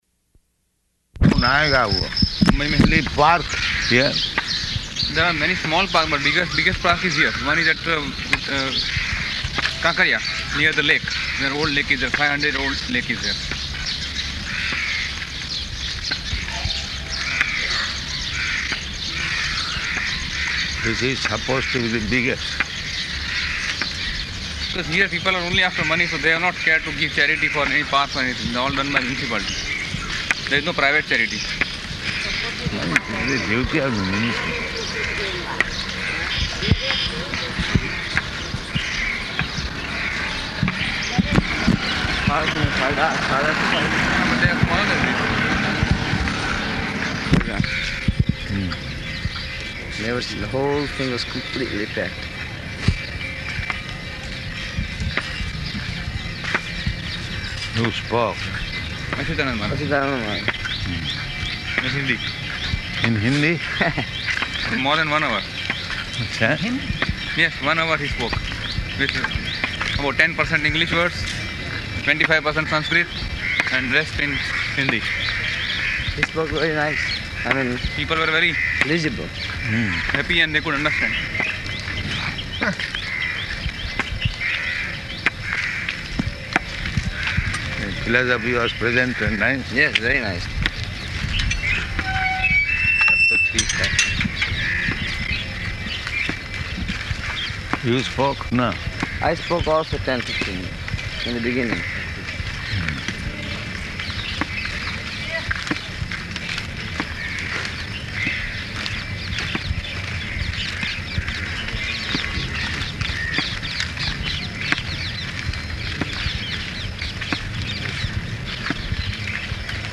Morning Walk --:-- --:-- Type: Walk Dated: September 27th 1975 Location: Ahmedabad Audio file: 750927MW.AHM.mp3 Prabhupāda: [Hindi] ...parks here?